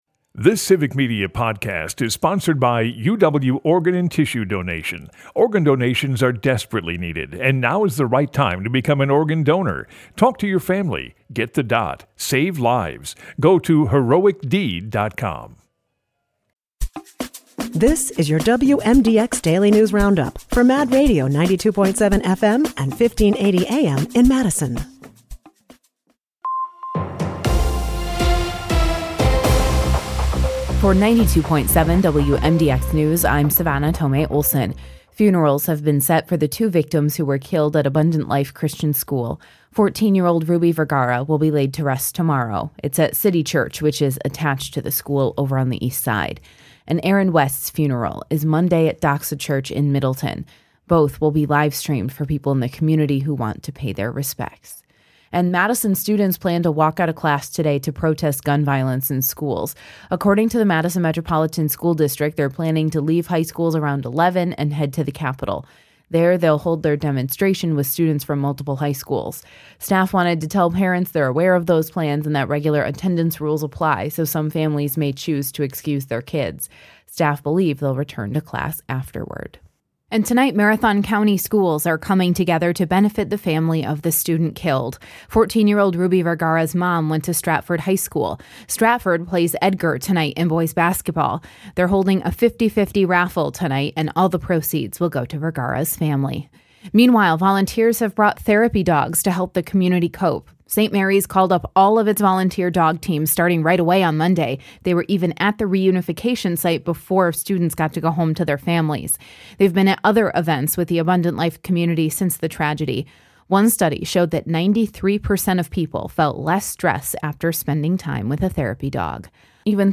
The WMDX Mad Radio Daily News Roundup has your state and local news, weather, and sports for Madison, delivered as a podcast every weekday at 9 a.m. Stay on top of your local news and tune in to your community!